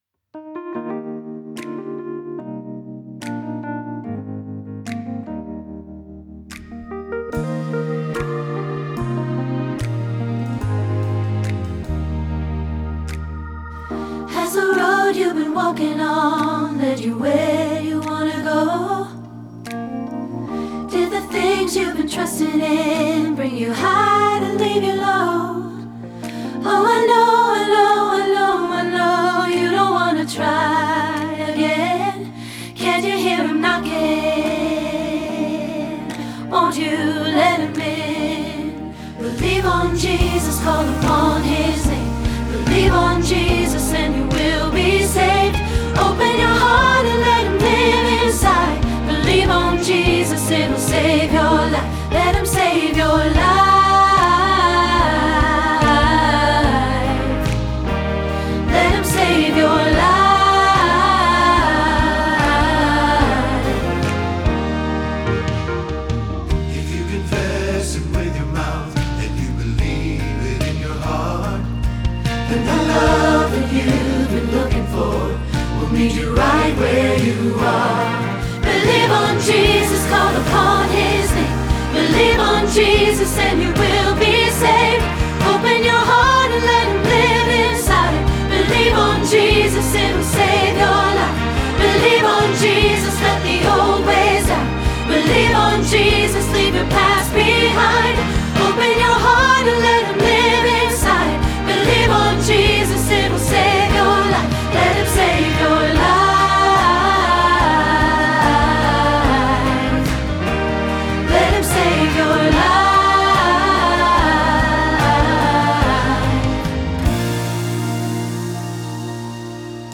Believe on Jesus – Soprano – Hilltop Choir
Believe on Jesus – Soprano Hilltop Choir
Believe-on-Jesus-Soprano.mp3